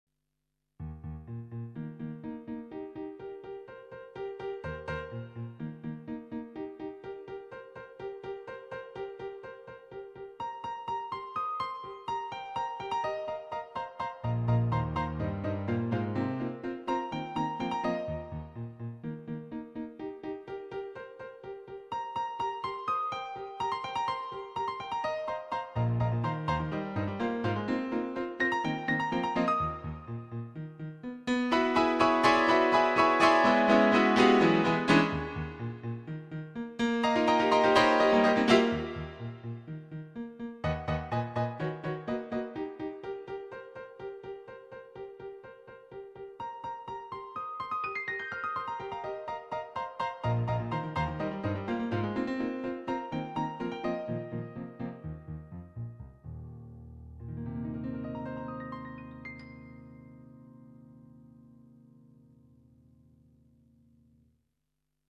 nhạc độc tấu
cho đàn piano độc tấu
dân ca Xá